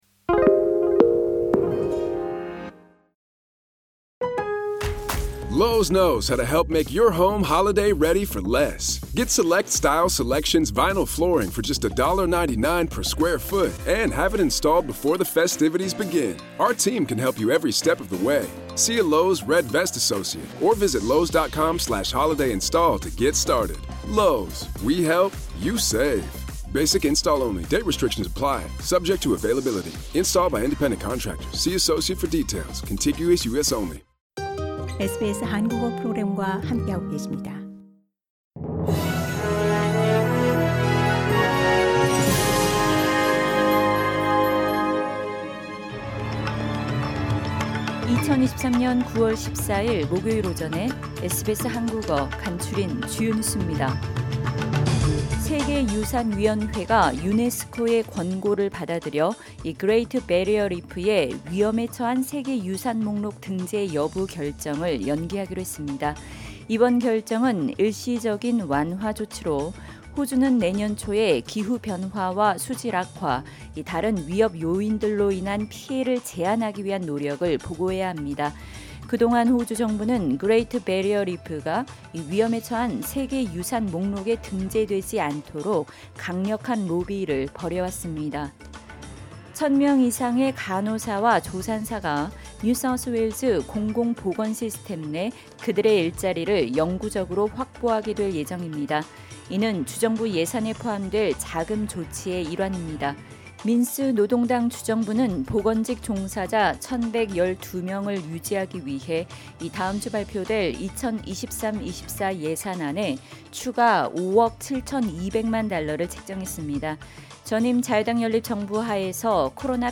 2023년 9월14일 목요일 아침 SBS 한국어 간추린 주요 뉴스입니다.